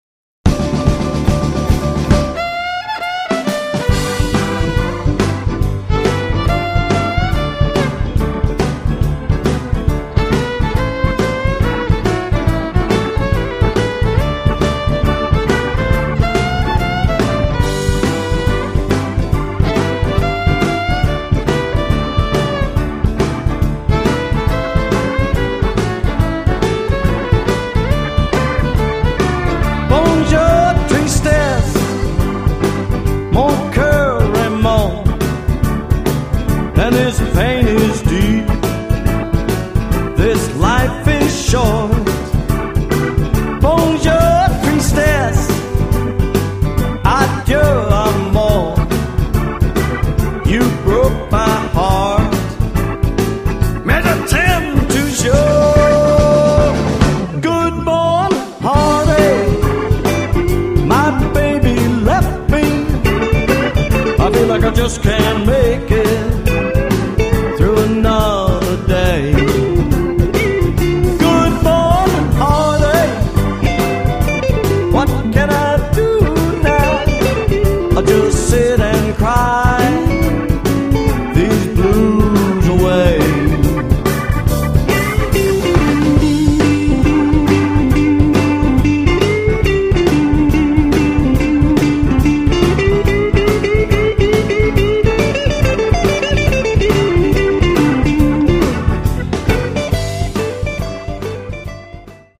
to Swamp Blues
violin
relentless drive